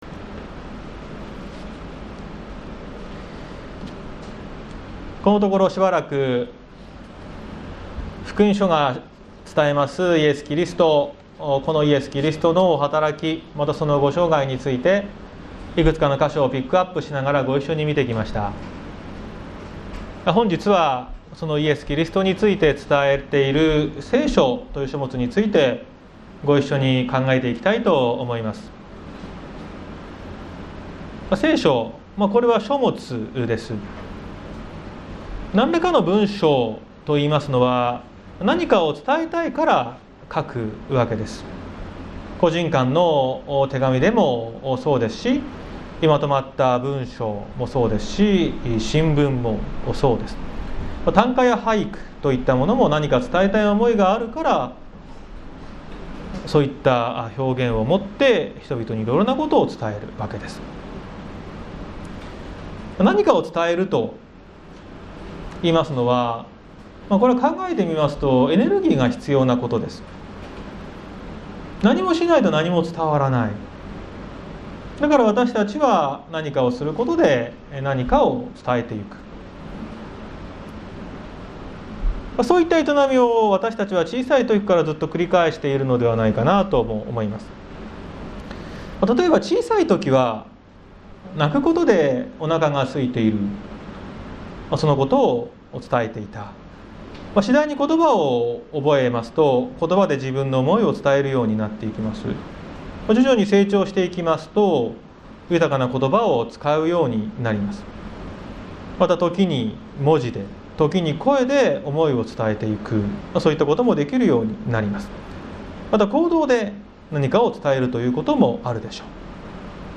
綱島教会。説教アーカイブ。
毎週日曜日の10時30分から神様に祈りと感謝をささげる礼拝を開いています。